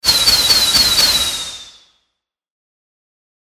/ F｜演出・アニメ・心理 / F-30 ｜Magic 魔法・特殊効果
レーザー光線 5発 01Fastヒュヒュヒューン